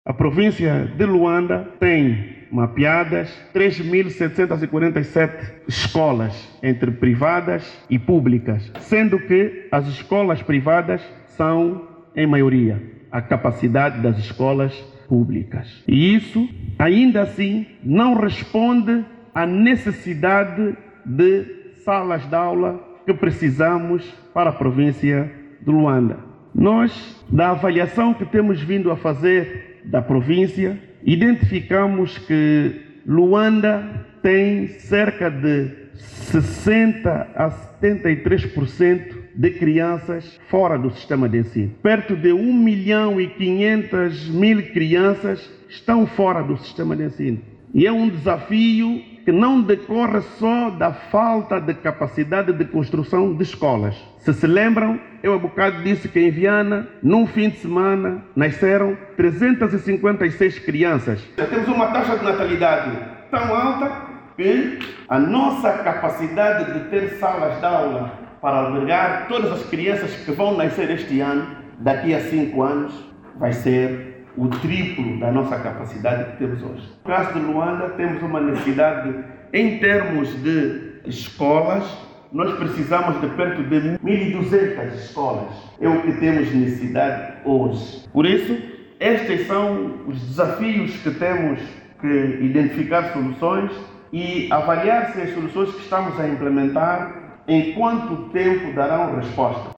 O dirigente fez esses pronunciamentos na Universidade Órcar Ribas numa palestra onde foi tema: os desafios da província de Luanda.
Oiça agora o aúdio do Governador.